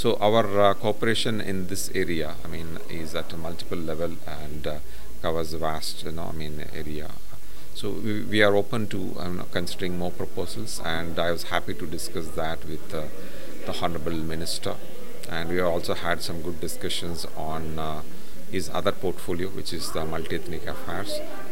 [Indian High Commissioner to Fiji, Palaniswamy Karthgeyan]